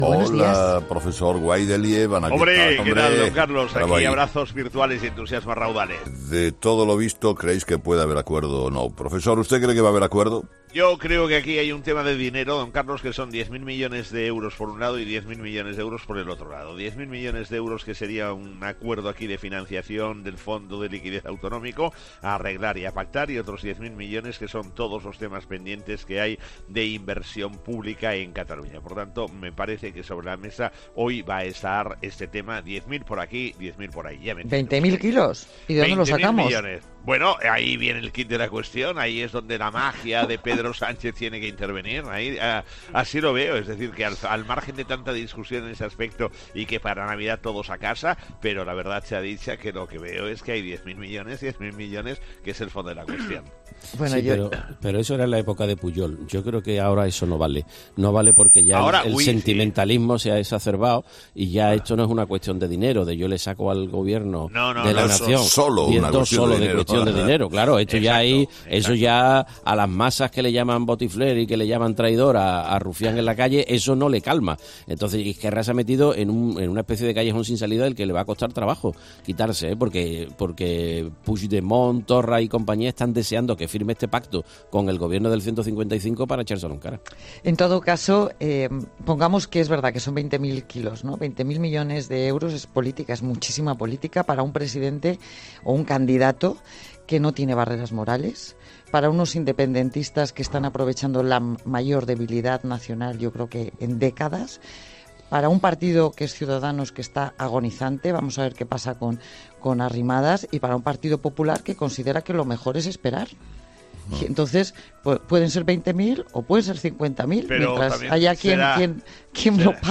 La actualidad económica en 'Herrera en COPE' con el profesor Gay de Liébana.